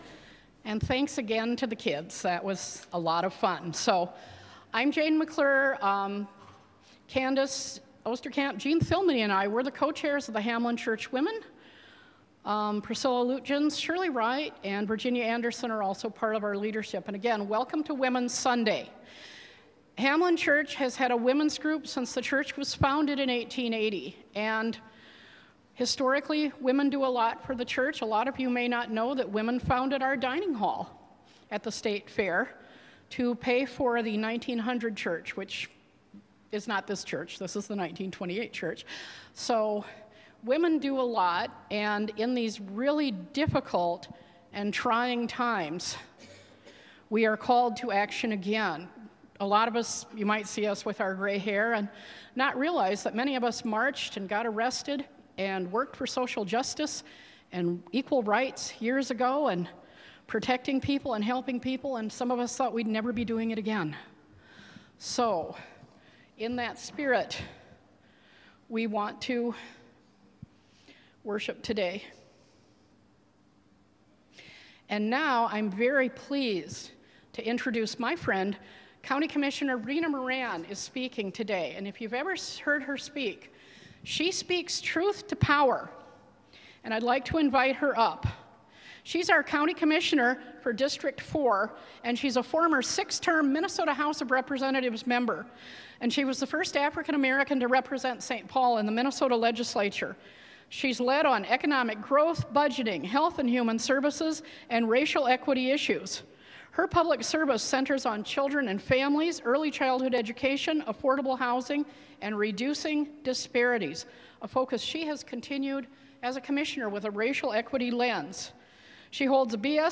Special guest speaker County Commissioner Rena Moran.